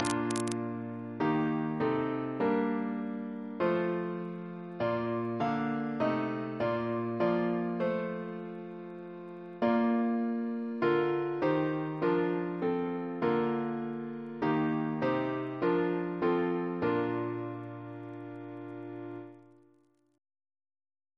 Double chant in B♭ Composer: Lt (RN) William Marsh (1757-1818), Organist of Chichester Cathedral; brother of John Reference psalters: ACB: 385; ACP: 351; H1940: 638; OCB: 16; PP/SNCB: 90; RSCM: 94